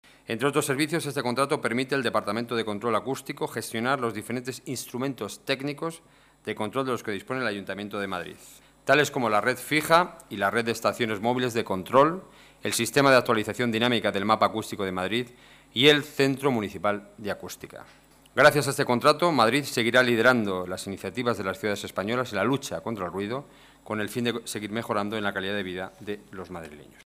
Nueva ventana:Declaraciones del portavoz del Gobierno municipal, Enrique Núñez